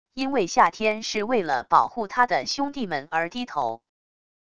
因为夏天是为了保护他的兄弟们而低头wav音频生成系统WAV Audio Player